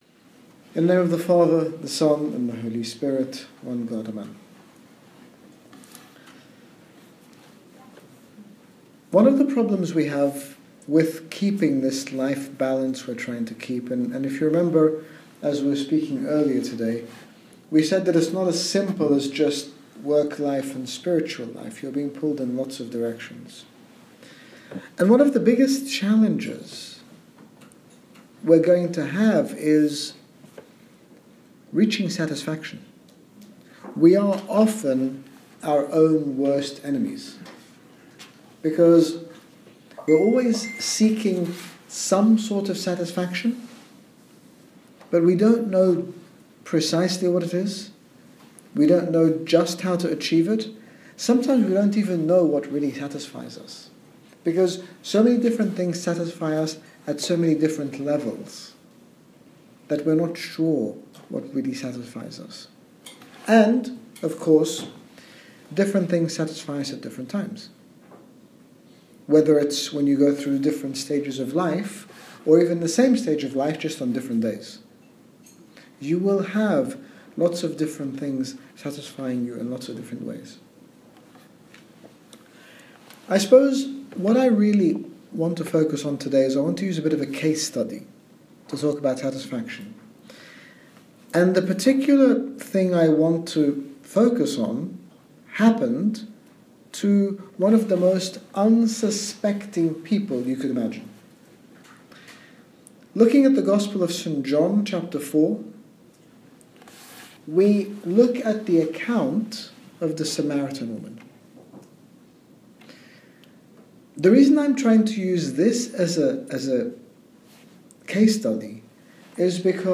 In this talk, His Grace Bishop Angaelos, General Bishop of the Coptic Orthodox Church in the United Kingdom, uses the Samaritan woman as an example of how we can seek true satisfaction in life, and explains that through being faithful to our calling and in serving others we can all find that satisfaction in God. Download Audio Read more about Satisfaction - Talk 2 - Pittsburg Agape 2015 Series
Satisfaction - Pittsburgh Agape 2015 T2.mp3